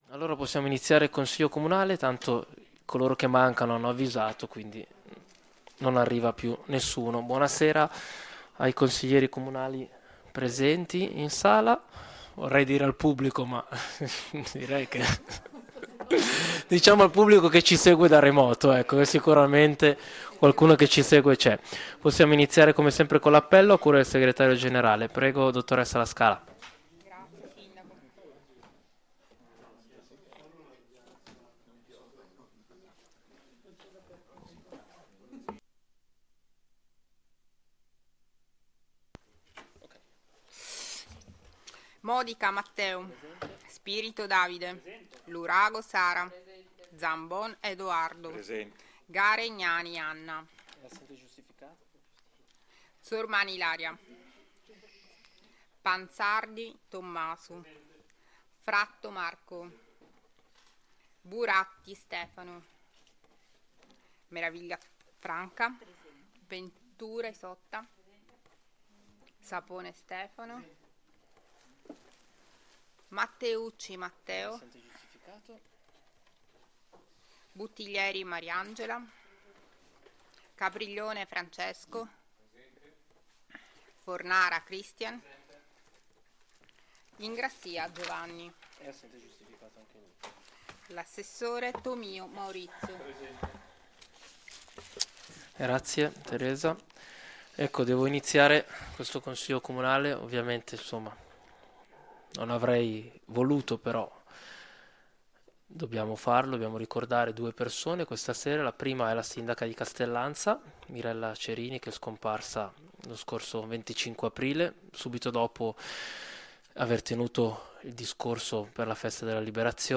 Streaming Audio in diretta del Consiglio Comunale.